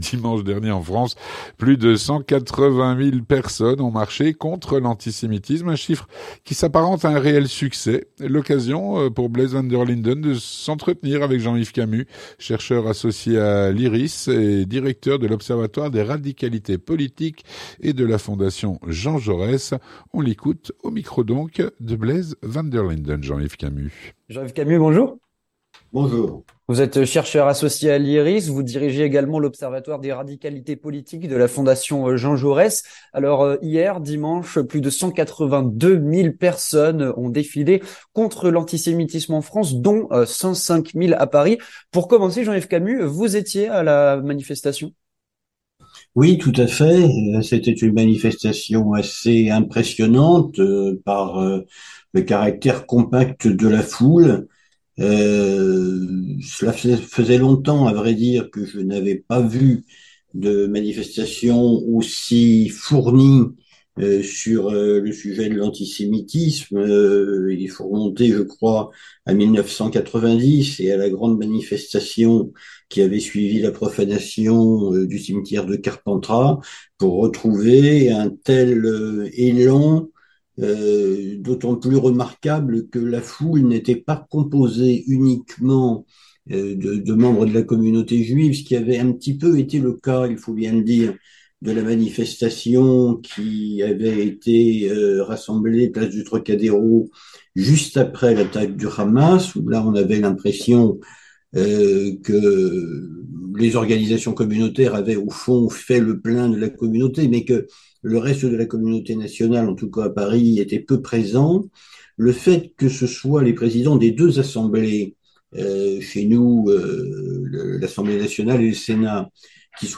L'entretien du 18H - Dimanche en France, plus de 180.000 personnes ont marché contre l'antisémitisme.